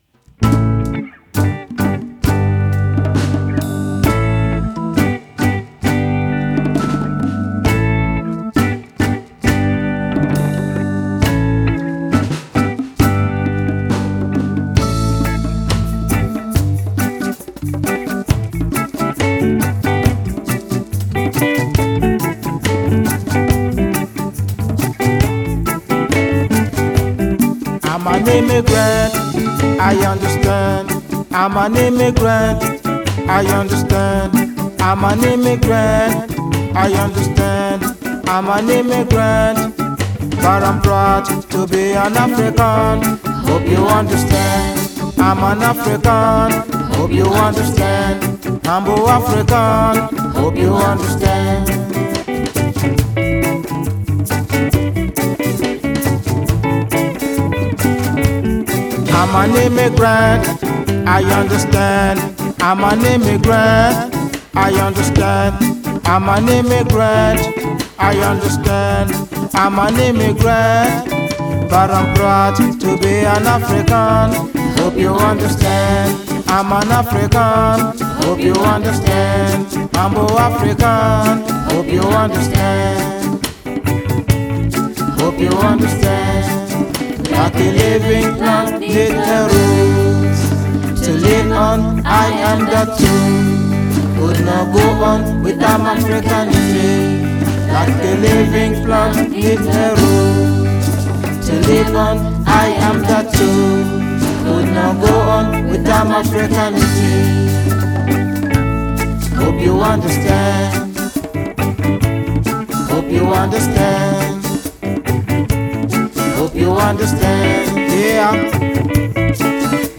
Reggae
Keyboard
Sax and Flute
Backing Vocals
Bass